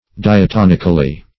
Meaning of diatonically. diatonically synonyms, pronunciation, spelling and more from Free Dictionary.
diatonically - definition of diatonically - synonyms, pronunciation, spelling from Free Dictionary Search Result for " diatonically" : The Collaborative International Dictionary of English v.0.48: Diatonically \Di`a*ton"ic*al*ly\, adv.